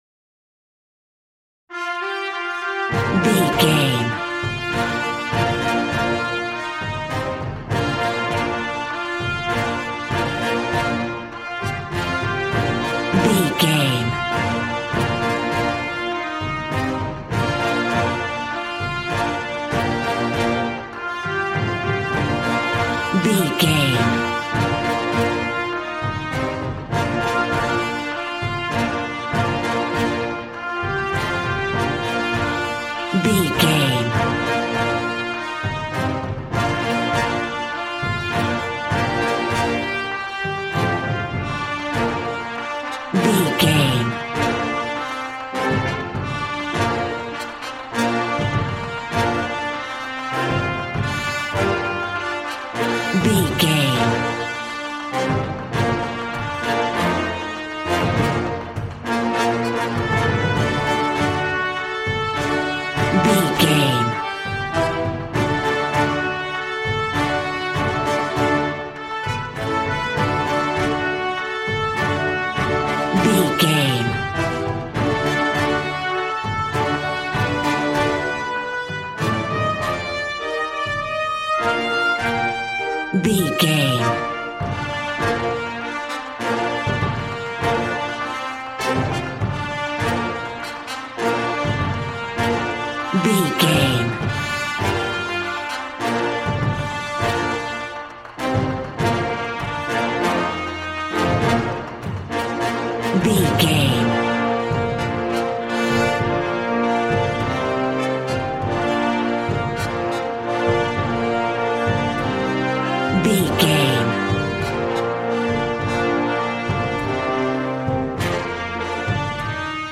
Valiant and Triumphant music for Knights and Vikings.
Regal and romantic, a classy piece of classical music.
Ionian/Major
B♭
brass
strings
violin
regal